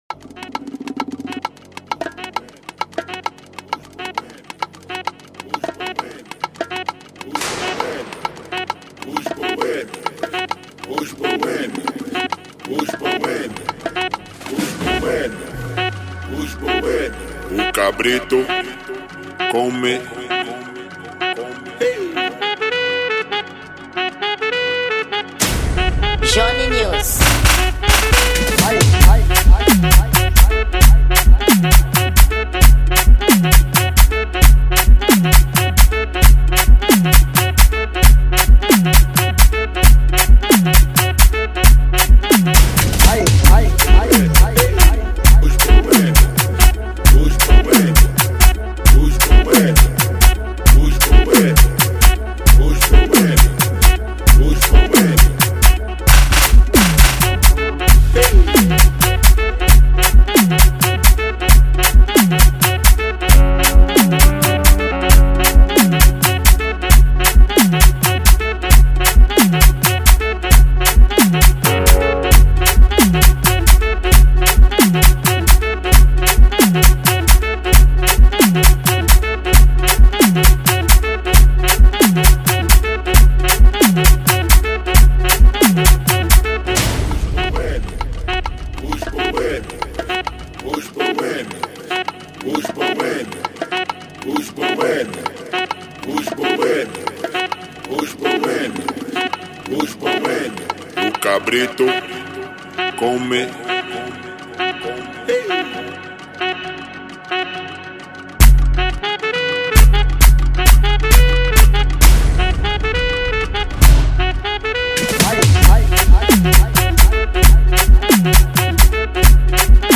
Gênero: Afro House